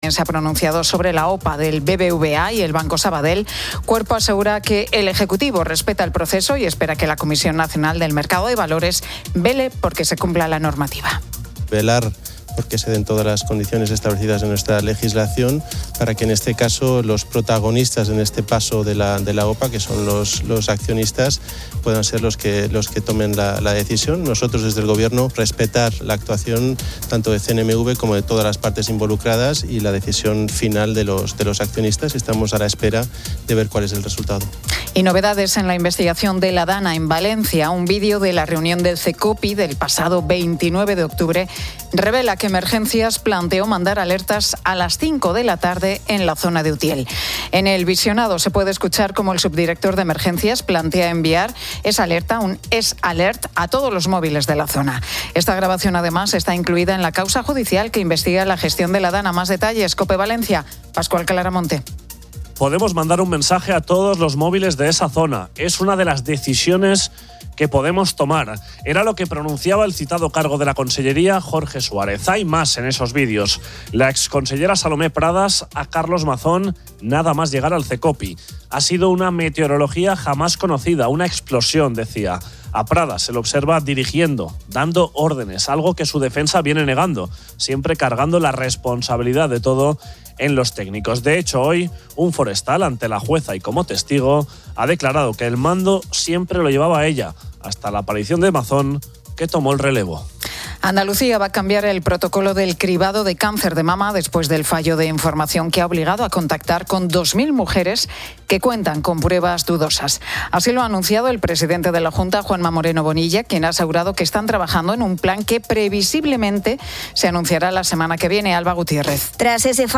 La Tarde 18:00H | 03 OCT 2025 | La Tarde Pilar García Muñiz entrevista al cantautor Iván Ferreiro.